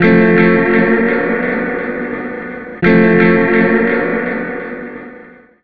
Index of /90_sSampleCDs/Zero-G - Total Drum Bass/Instruments - 1/track35 (Guitars)
09 Blue Slide 170 A.wav